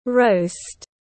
Thịt quay tiếng anh gọi là roast, phiên âm tiếng anh đọc là /rəʊst/